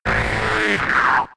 Cri de Chrysacier K.O. dans Pokémon X et Y.